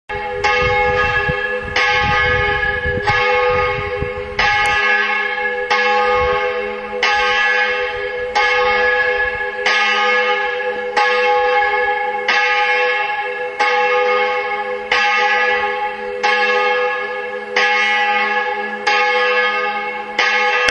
Ton Siebnerin - Die Glocken der Pfarrkirche Marling